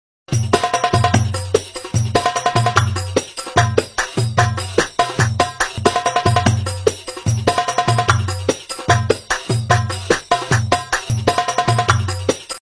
Baby Playing Drum Bebê Sound Effects Free Download